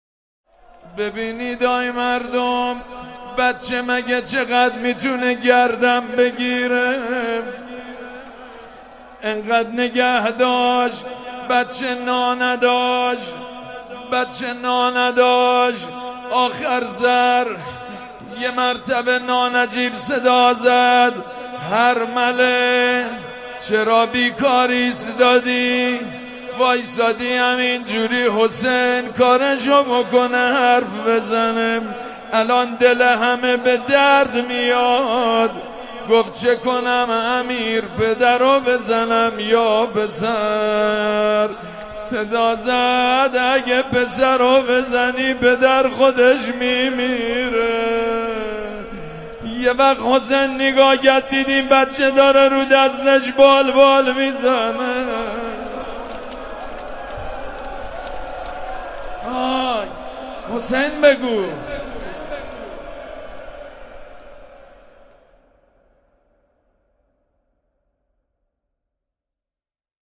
حسینیه ریحانة‌الحسین(س)، پارک ارم